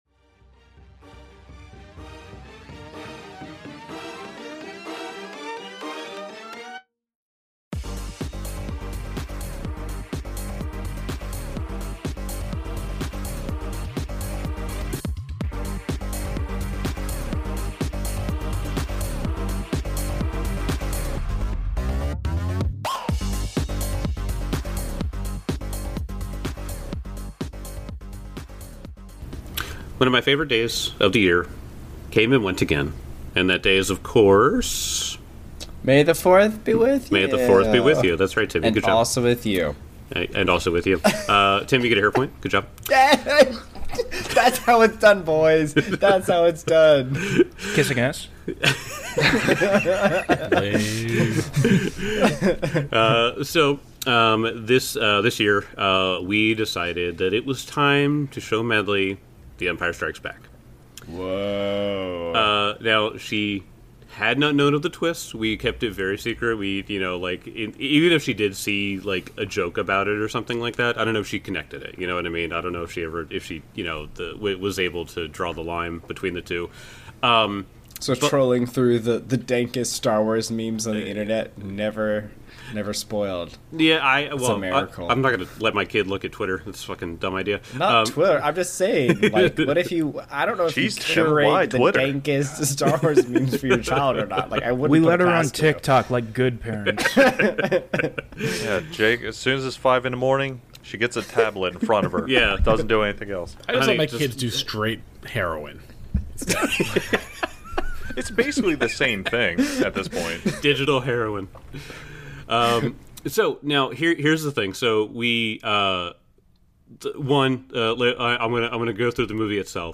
Actual play podcast of the Pathfinder 2e, Age of Ashes adventure path produced by Paizo. Five nerdy best friends who love to play pretend with dice!